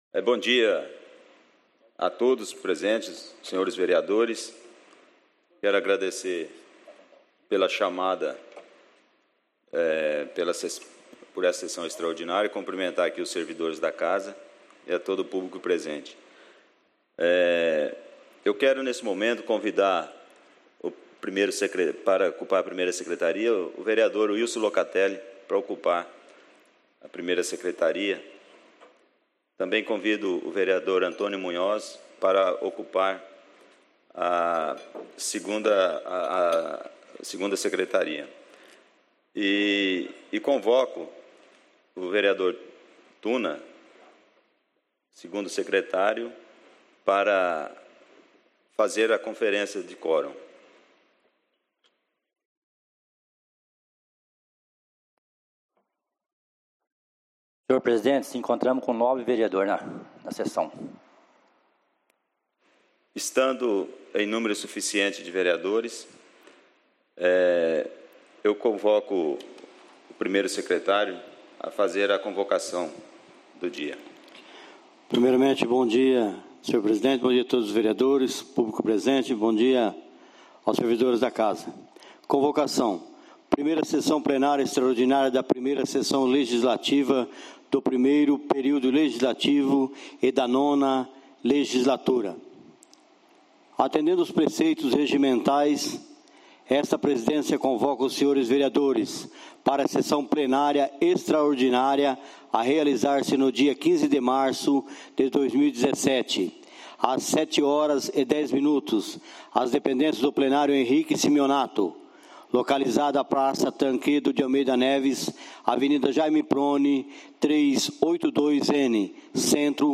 Áudio na íntegra da Sessão Ordinária realizada no dia 15/03/2017 as 07 horas no Plenário Henrique Simionatto.